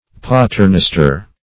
Paternoster \Pa"ter*nos`ter\, n. [L., Our Father.]